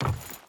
Footsteps / Wood
Wood Chain Run 4.wav